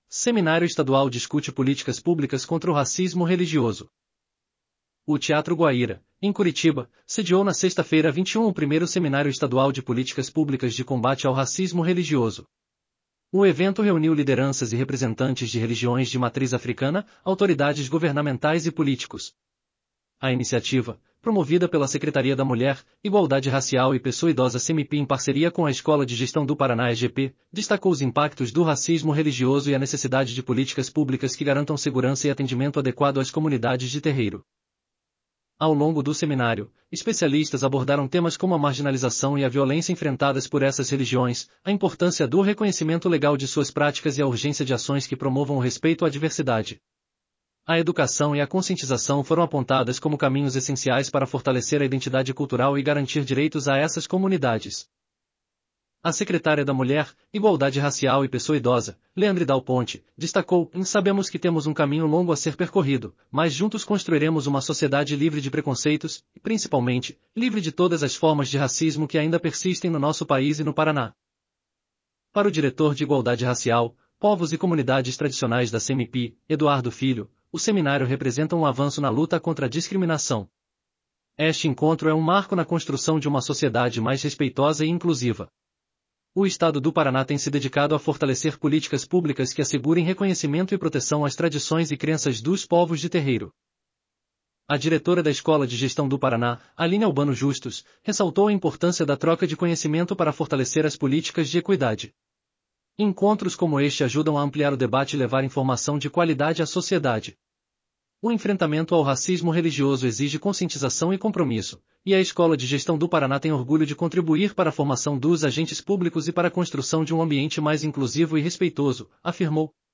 seminario_estadual_discute_politicas_publicas_audio_noticia.mp3